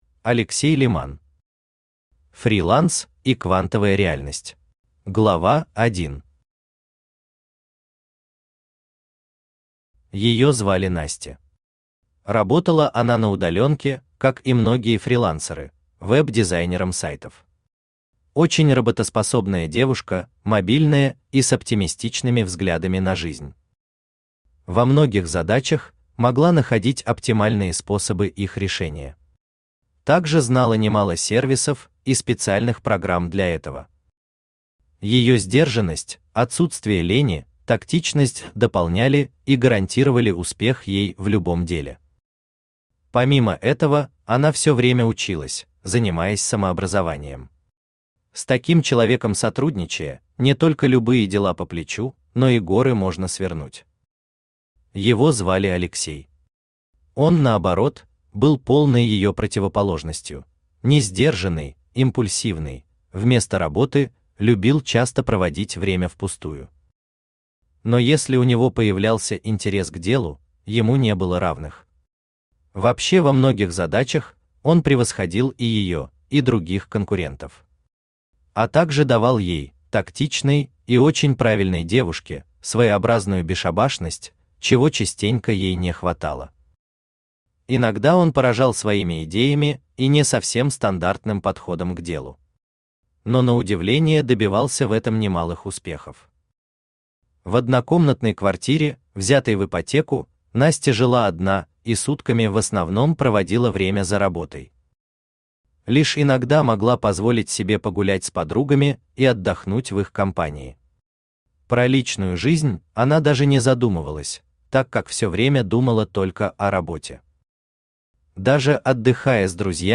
Аудиокнига Фриланс, я люблю тебя | Библиотека аудиокниг
Aудиокнига Фриланс, я люблю тебя Автор Алексей Леман Читает аудиокнигу Авточтец ЛитРес.